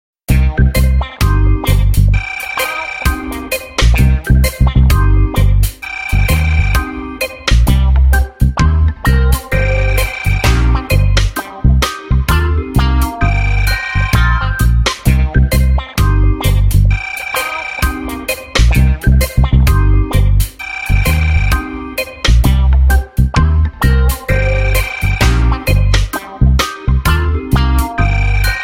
Reggae Music And Ringing.